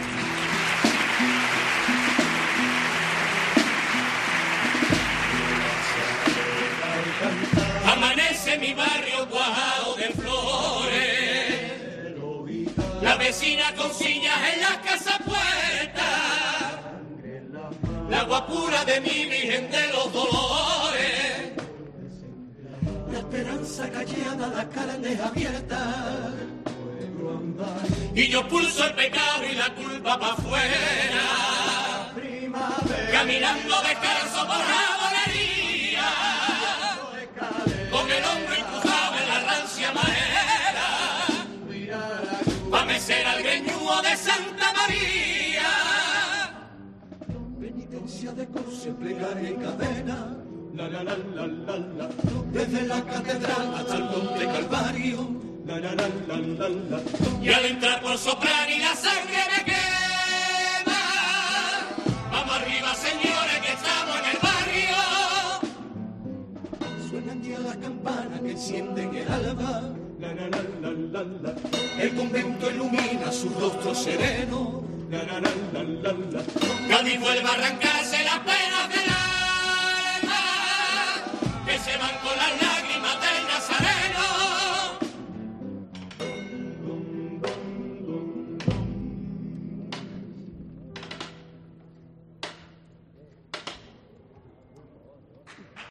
Cuarteta de la comparsa 'Los veleros, una comparsa de toda la vida'
Carnaval